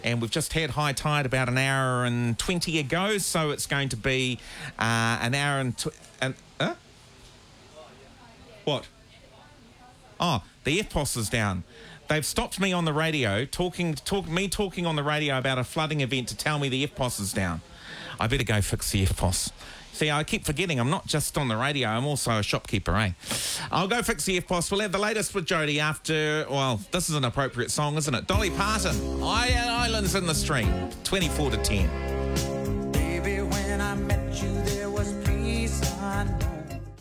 Overall, a great example of what “live, local radio” actually sounds like - imperfect, human, community-focused and invaluable during significant local events.
When Whananaki FM goes live to air from a general store, its studio was not fully soundproofed.